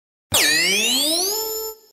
Catchphrase Buzzer Sound Button - Sound Effect Button